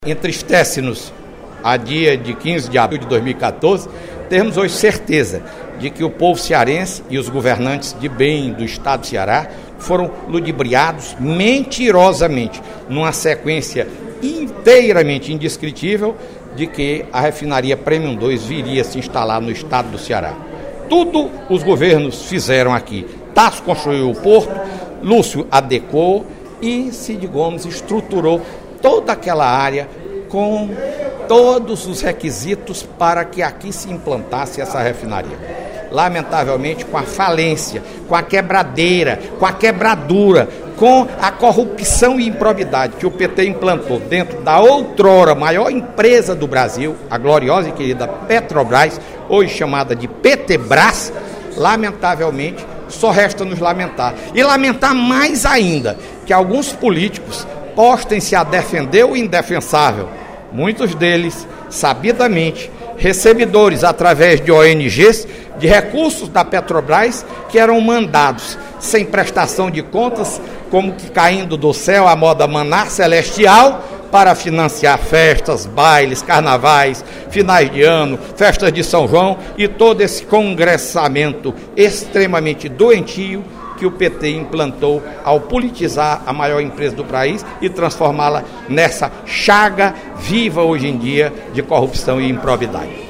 No primeiro expediente da sessão desta terça-feira (15/04), o deputado Fernando Hugo (SDD) afirmou que a população do Estado foi enganada pelo ex-presidente Lula e pela presidente Dilma sobre a vinda da Refinaria Premium II para o Ceará.